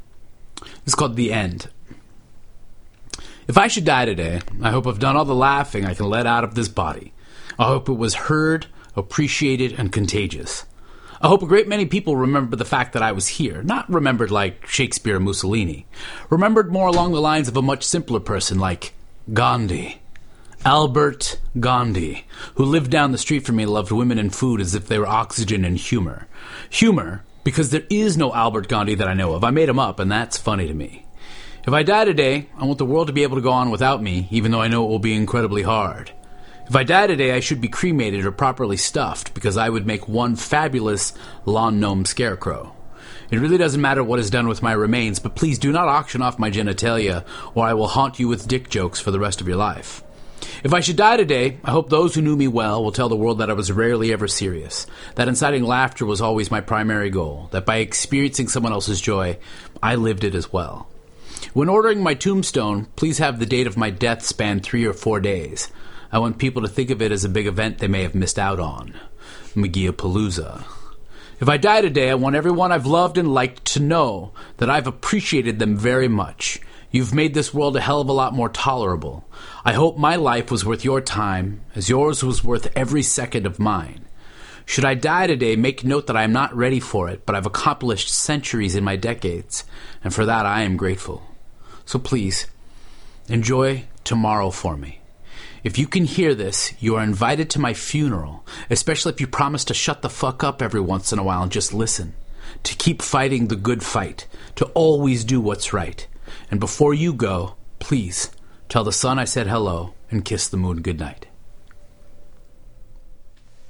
His poems fall from his mouth the way jazz comes from a horn: smooth, intentional, with soul.